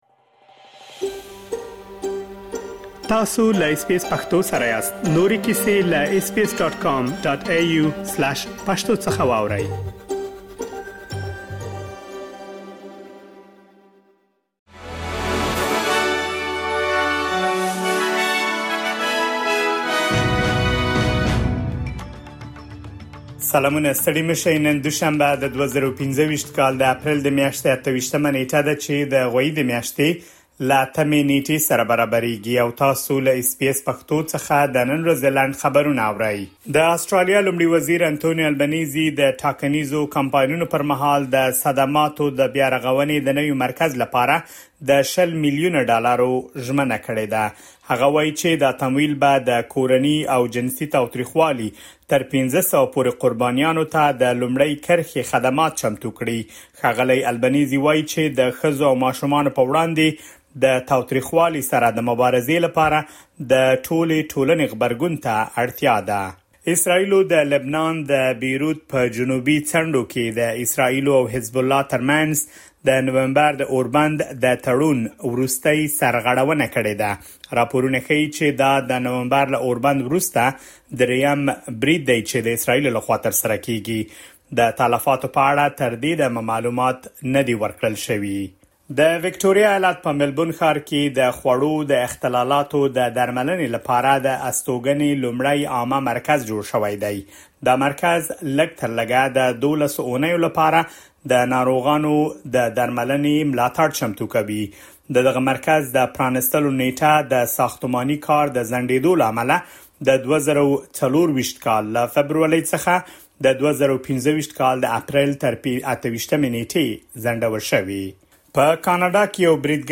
د اس بي اس پښتو د نن ورځې لنډ خبرونه | ۲۸ اپریل ۲۰۲۵
د اس بي اس پښتو د نن ورځې لنډ خبرونه دلته واورئ.